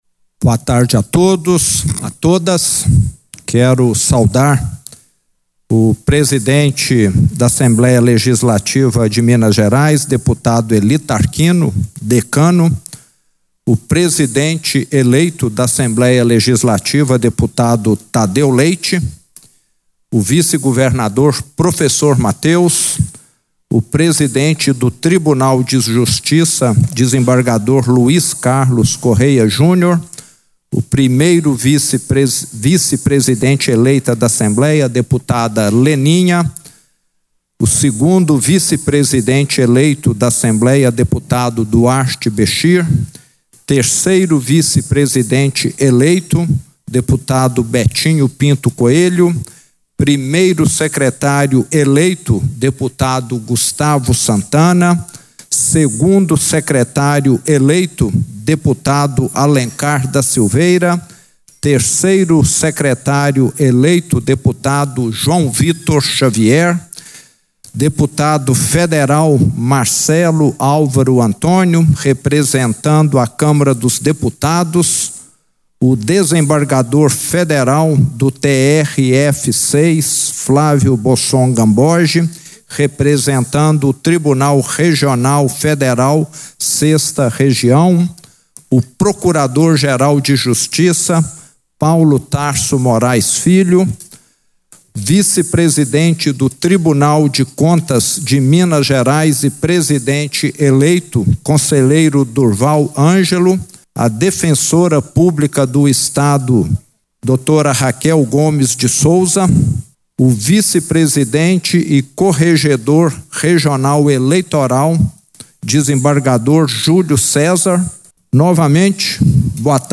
O Governador de Minas discursou durante a instalação da 3ª Sessão Legislativa da 20ª Legislatura quando falou sobre novos desafios
Discursos e Palestras